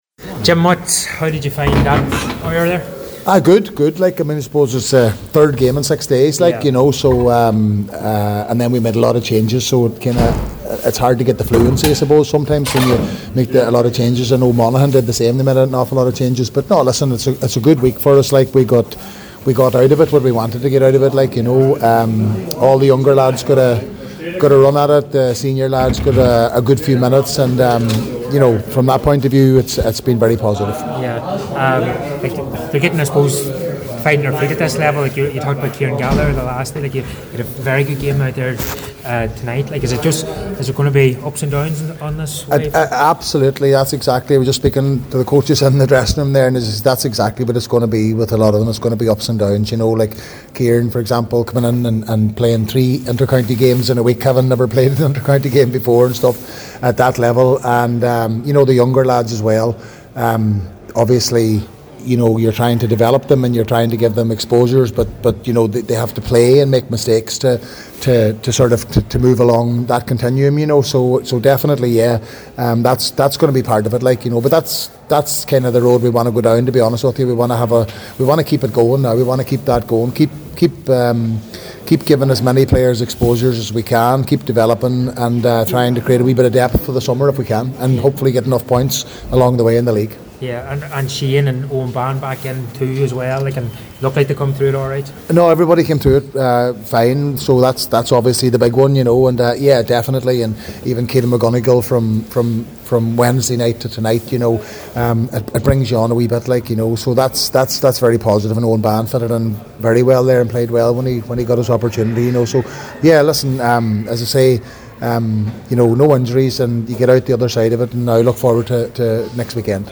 Donegal manager Jim McGuinness